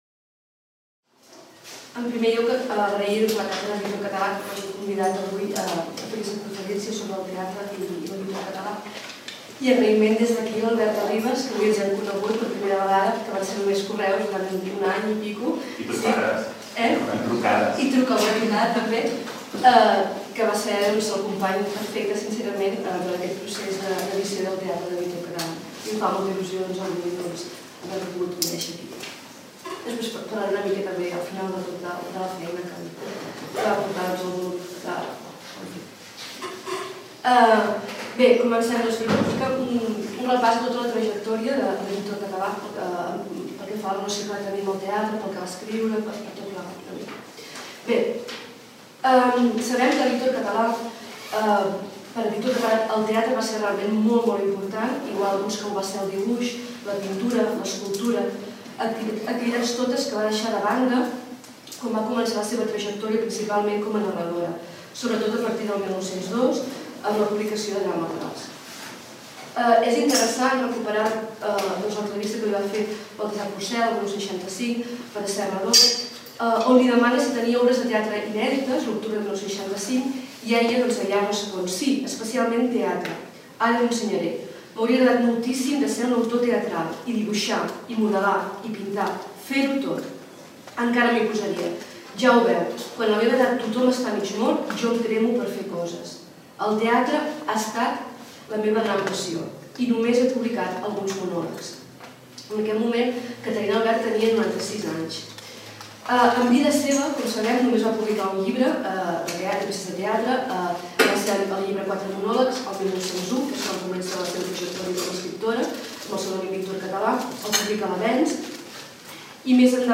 Conferència d'obertura: El teatre de Víctor Català